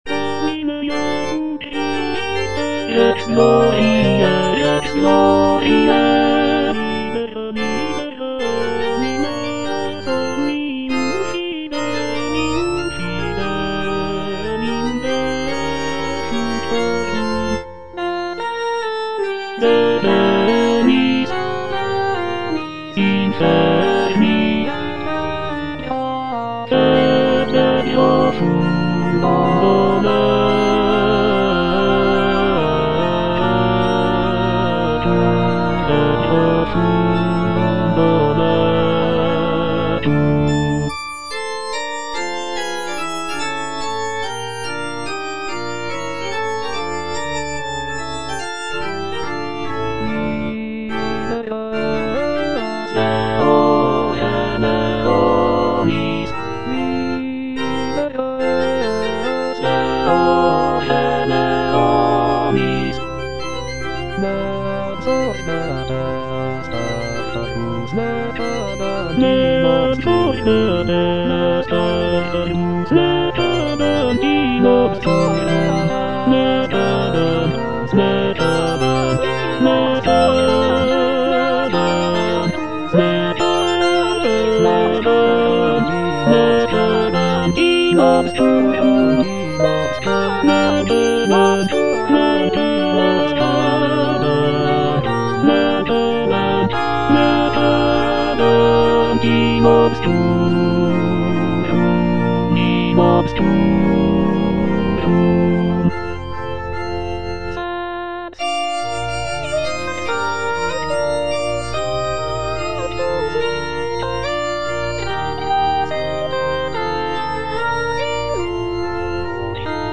M. HAYDN - REQUIEM IN C (MISSA PRO DEFUNCTO ARCHIEPISCOPO SIGISMUNDO) MH155 Domine Jesu Christe - Tenor (Emphasised voice and other voices) Ads stop: auto-stop